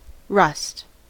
rust: Wikimedia Commons US English Pronunciations
En-us-rust.WAV